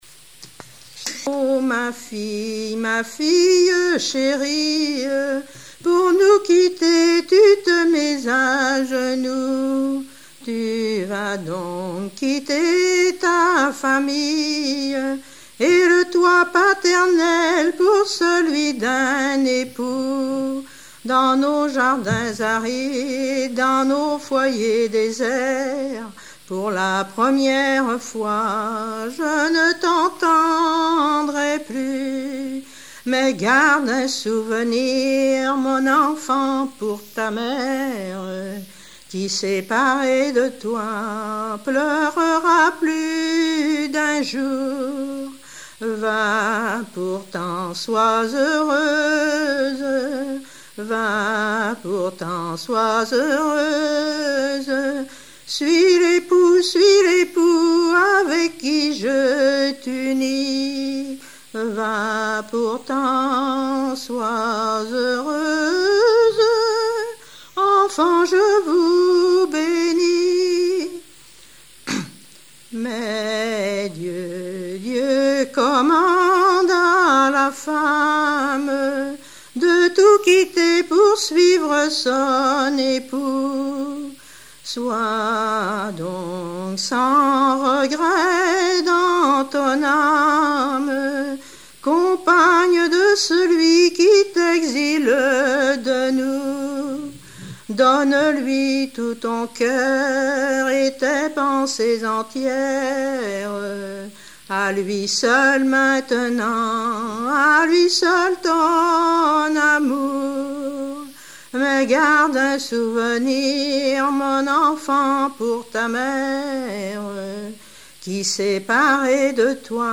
circonstance : fiançaille, noce
Genre strophique
Répertoire de chansons populaires et traditionnelles
Pièce musicale inédite